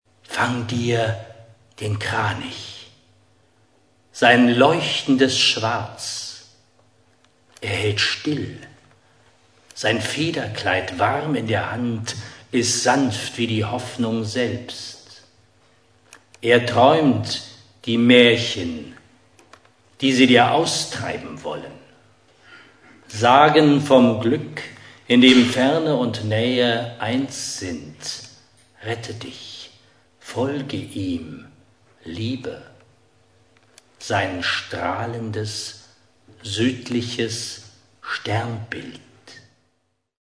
Die mit "Audioclip" gekennzeichneten mp3-Stücke enthalten kleine Ausschnitte aus dem literarisch-musikalischen Programm